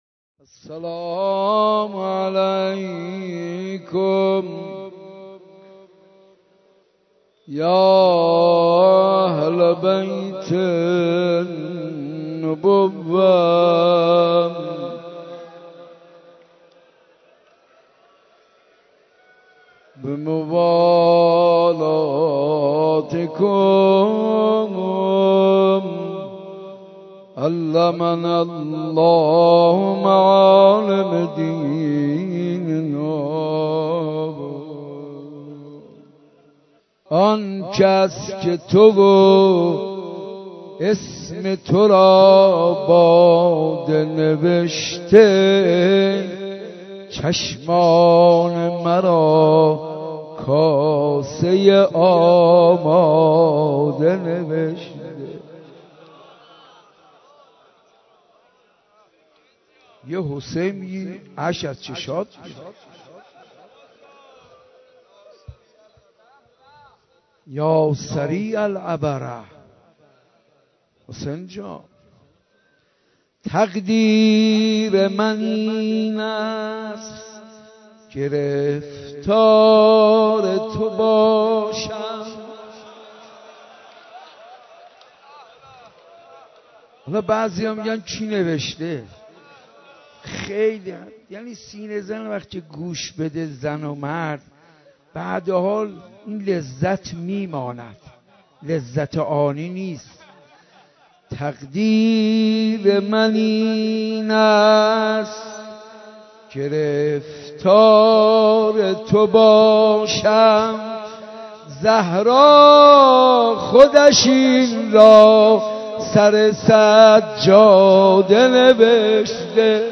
(تا شب هشتم با کیفیت بالا اضافه شد)